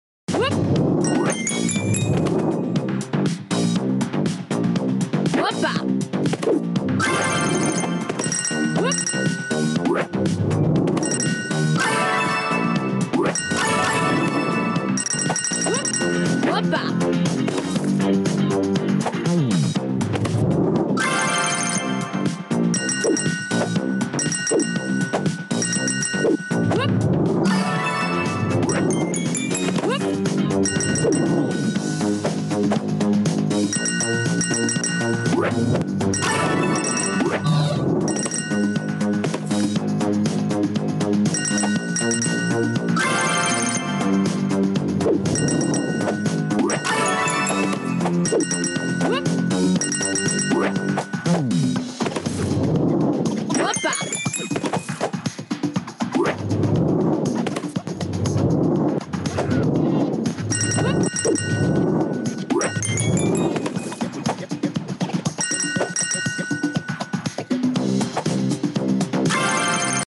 Mobile Game